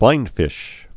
(blīndfĭsh)